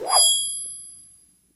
bp_snout_coin_fly_02.ogg